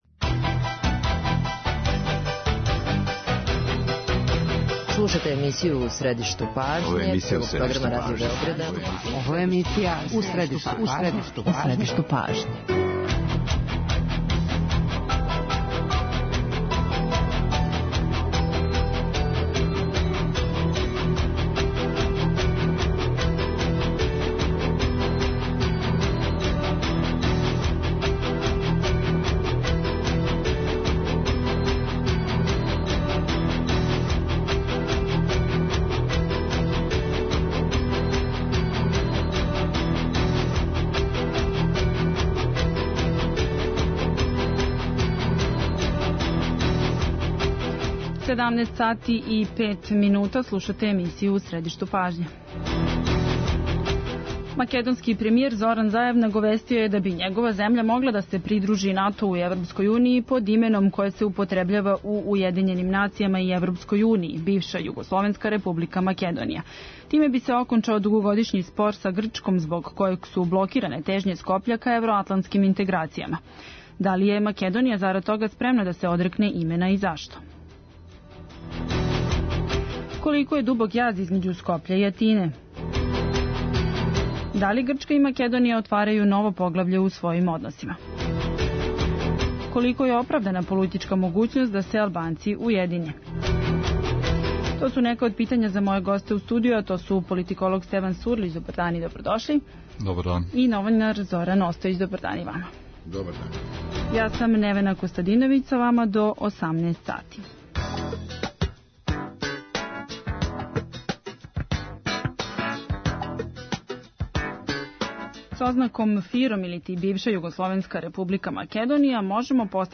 Из Скопља се укључује Роберт Алагјозовски, нови министар културе у македонској влади.